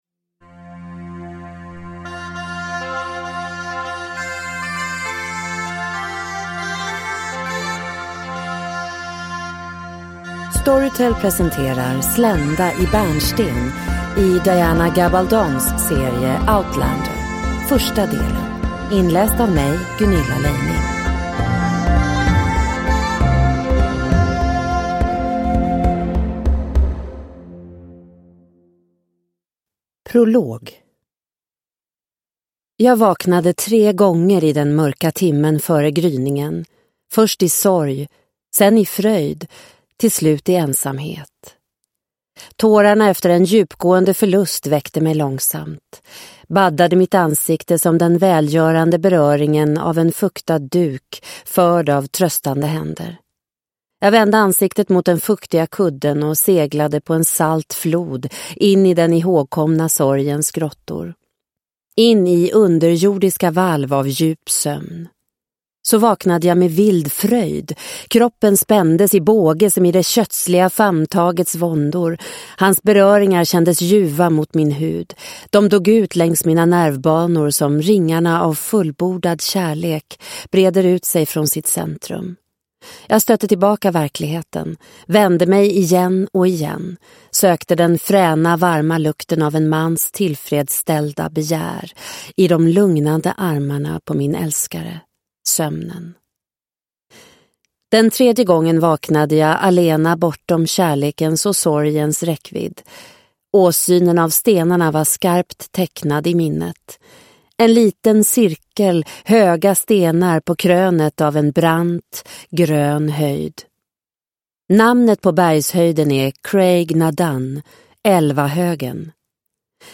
Slända i bärnsten - del 1 – Ljudbok – Laddas ner